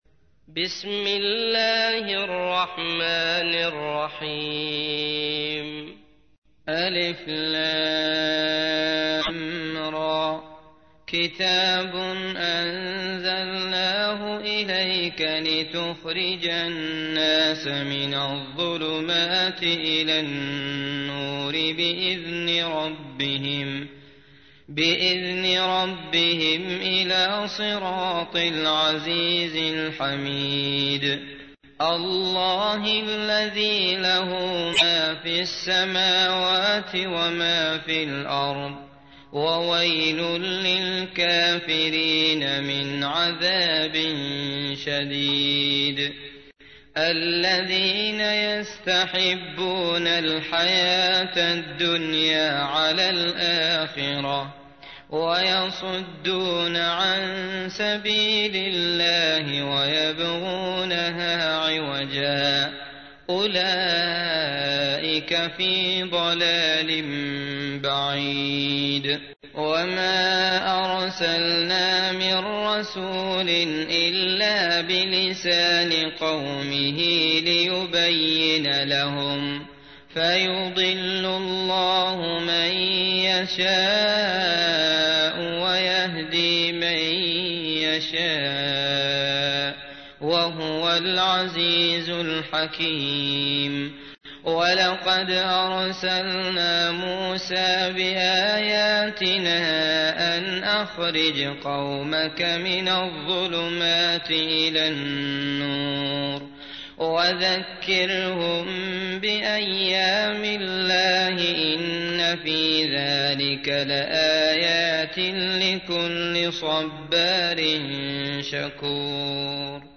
تحميل : 14. سورة إبراهيم / القارئ عبد الله المطرود / القرآن الكريم / موقع يا حسين